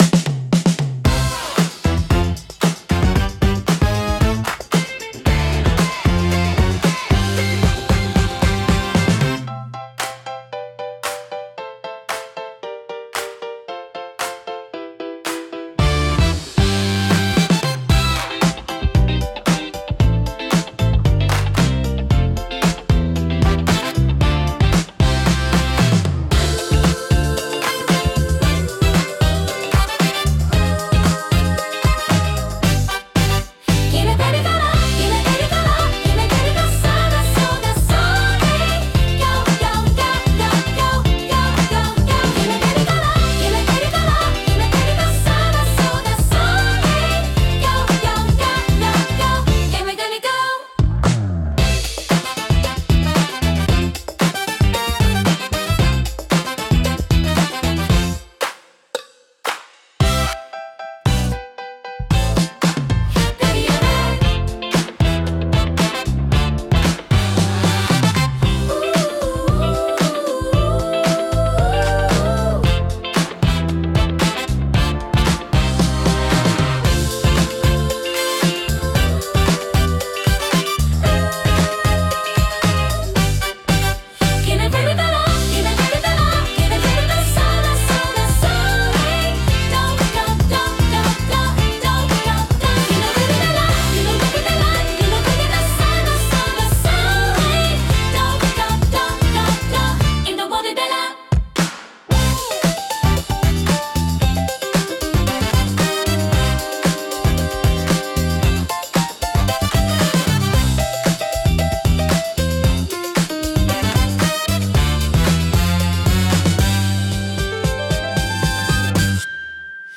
躍動感と活気に満ちたジャンルです。